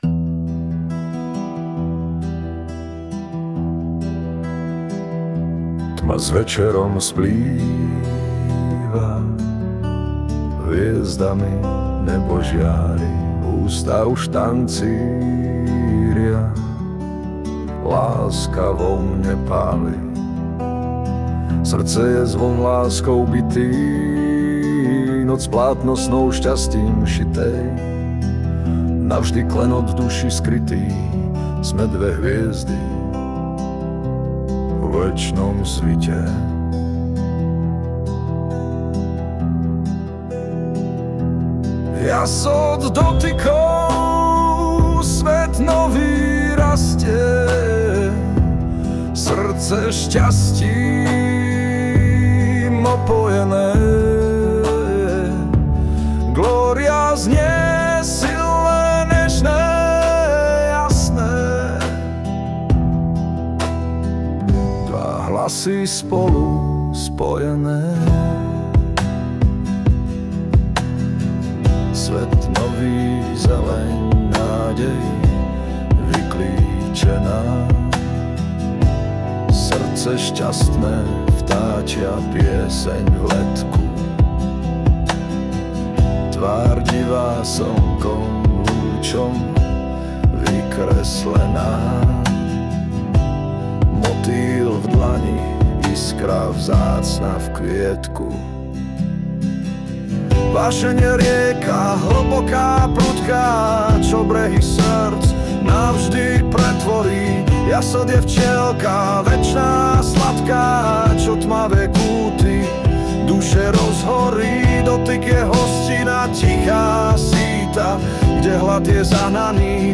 Hudba a spev AI
to je zabarvení (škoda, že je to AI - tohle je mužný hlas,